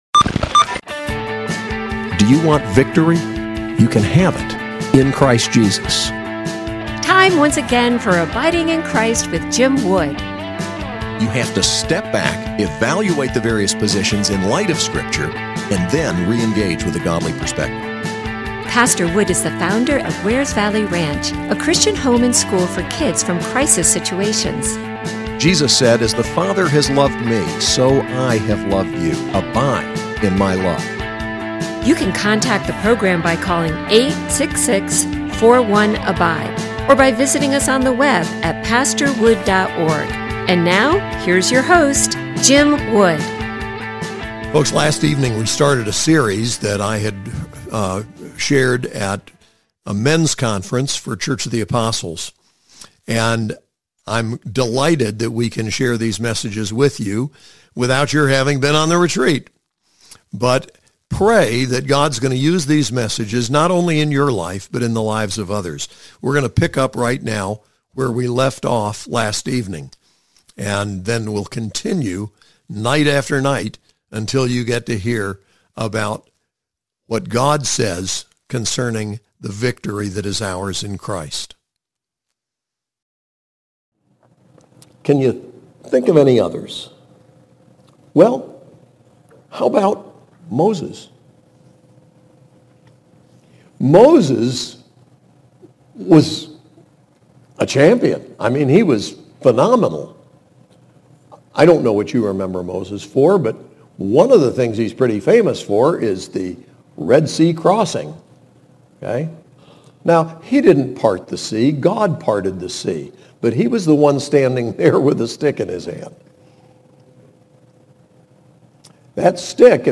Series: COA Men's Retreat